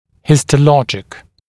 [hɪstə’lɔʤɪk][хистэ’лоджик]гистологический, тканевой